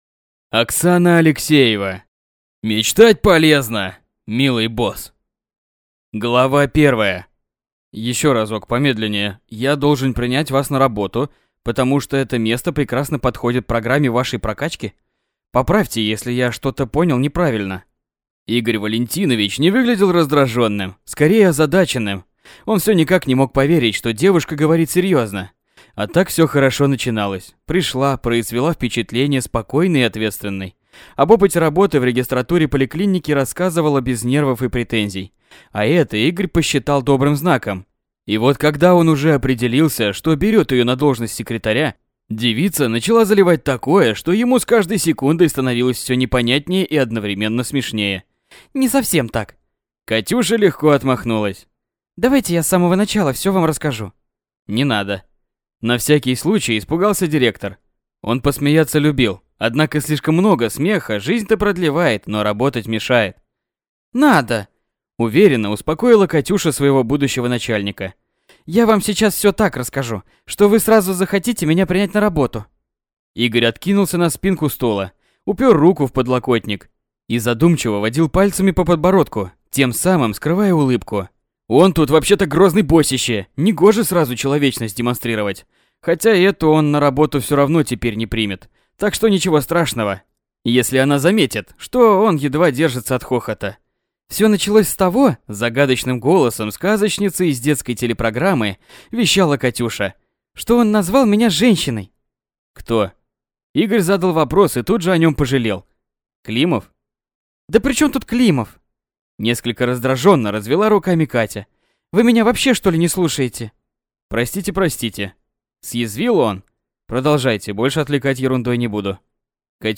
Аудиокнига Мечтать полезно, милый босс!